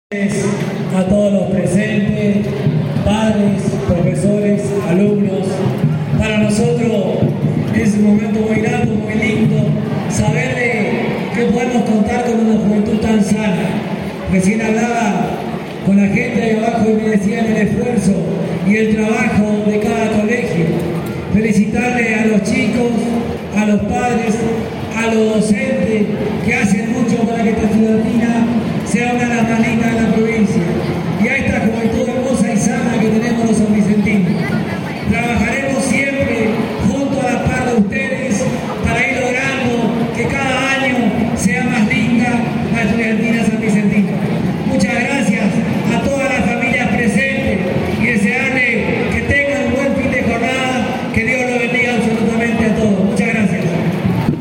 Este viernes 13, ya en el polideportivo comunal,  el broche de oro de esta edición, con la elección de reina y rey, pero además, con la consagración de los ganadores y la respectiva entrega de premios.
Audio de las palabras de bienvenida por parte del intendente Fabián Rodríguez